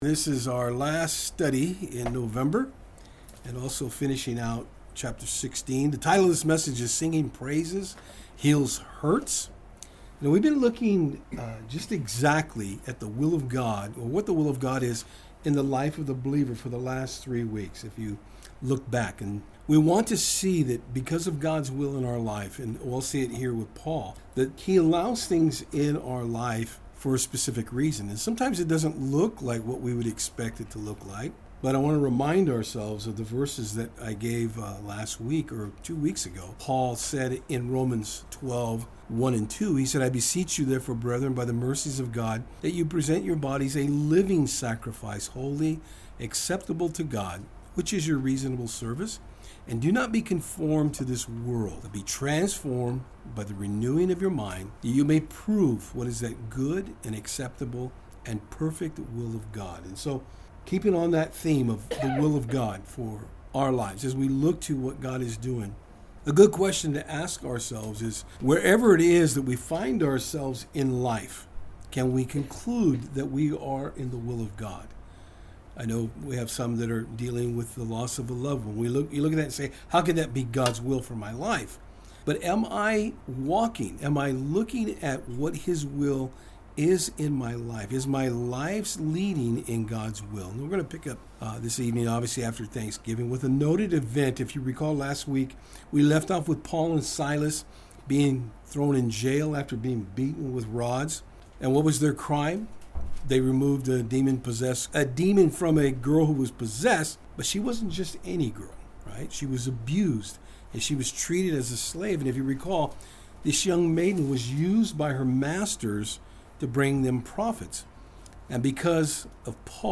(It’s Winter time the background noise is a pellet stove)